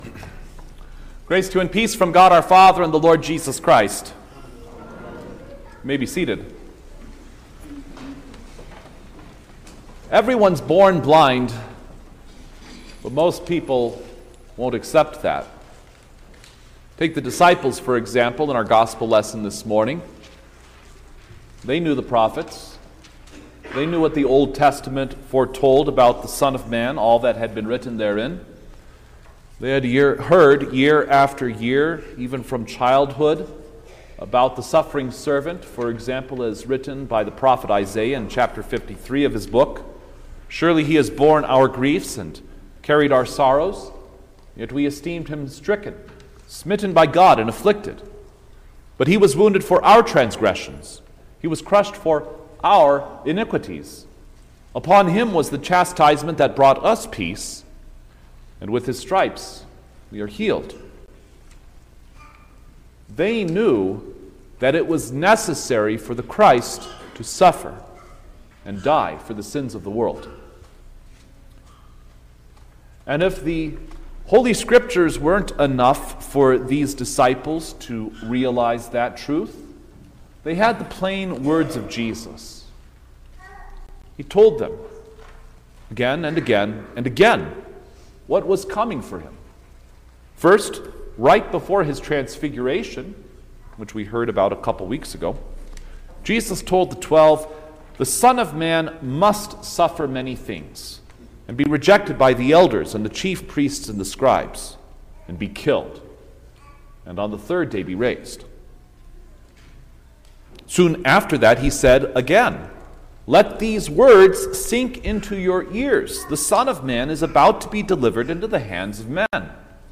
March-2_2025_Quinquagesima_Sermon-Stereo.mp3